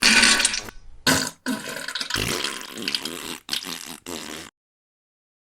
Fake Diarrhea
Fake Diarrhea is a free sfx sound effect available for download in MP3 format.
yt_xiClvyhsb34_fake_diarrhea.mp3